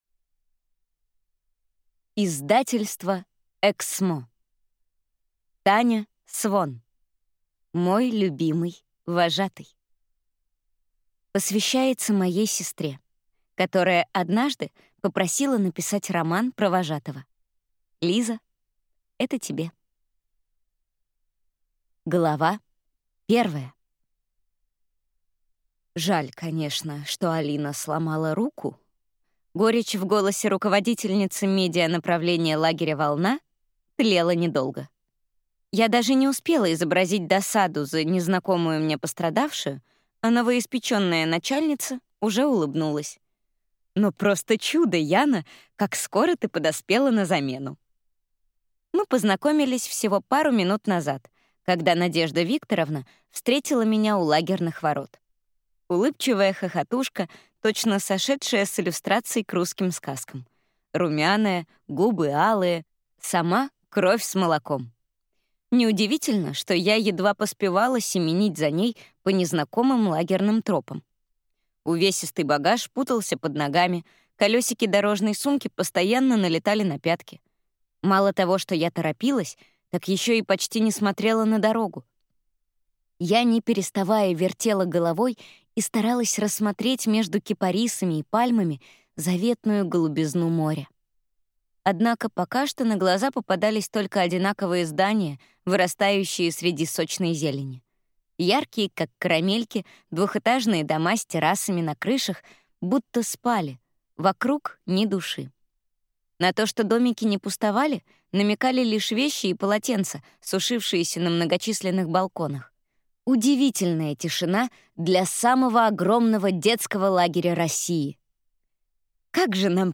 Аудиокнига Мой любимый вожатый | Библиотека аудиокниг
Aудиокнига Мой любимый вожатый